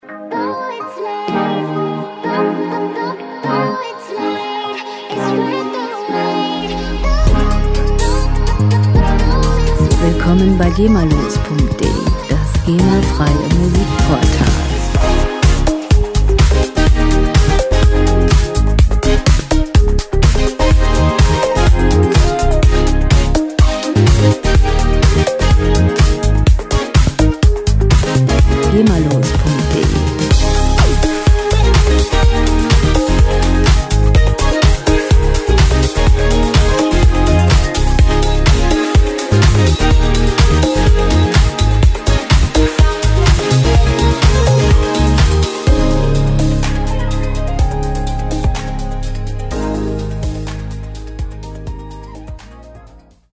Werbemusik - Fashion
Musikstil: J-Pop
Tempo: 125 bpm
Tonart: Es-Dur
Charakter: rhythmisch, lebendig
Instrumentierung: Synthesizer, Vocals